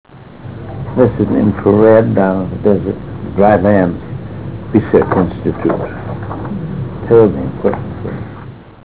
71Kb Ulaw Soundfile Hear Ansel Adams discuss this photo: [71Kb Ulaw Soundfile]